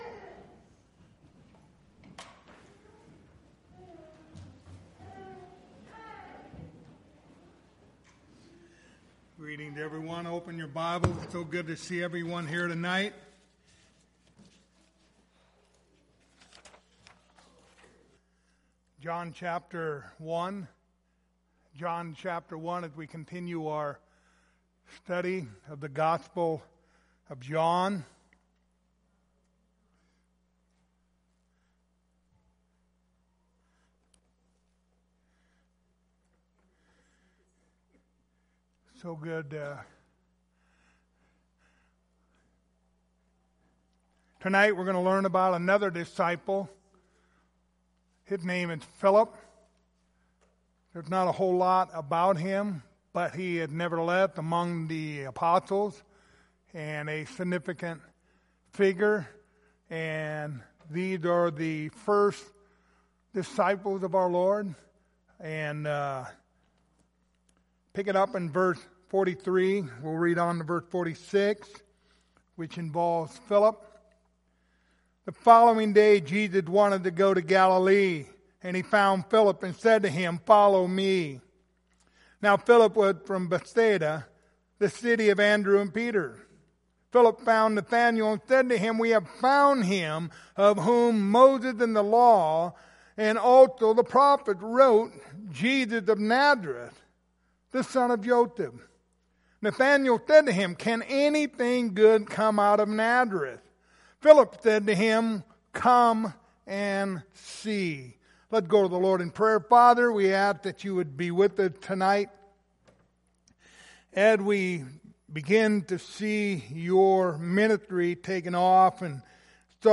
Passage: John 1:43-46 Service Type: Wednesday Evening